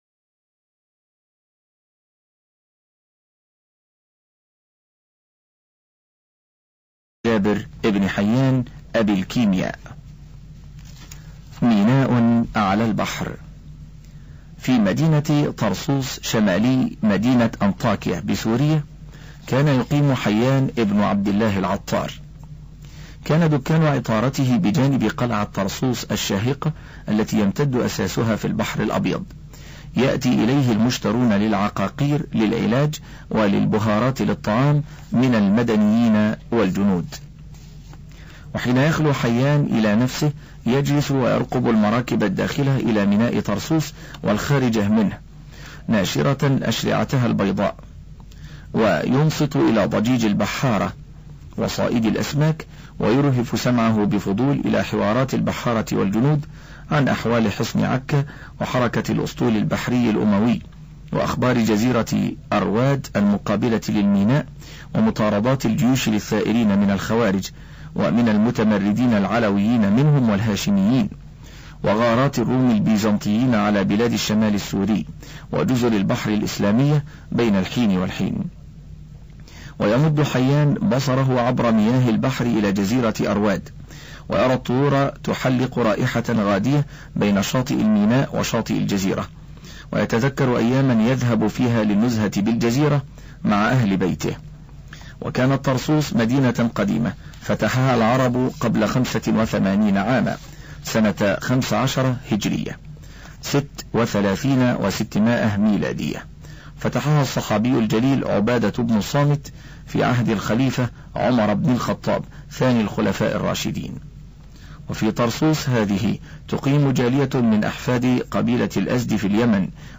أرشيف الإسلام - ~ الكتب مسموعة - كتب سير وتراجم - منوعات